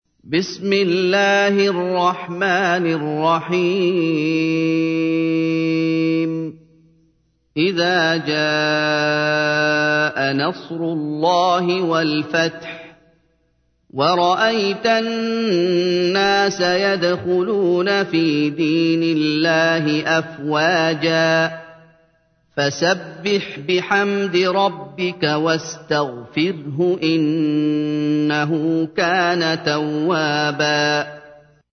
تحميل : 110. سورة النصر / القارئ محمد أيوب / القرآن الكريم / موقع يا حسين